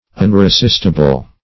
Unresistible \Un`re*sist"i*ble\, a.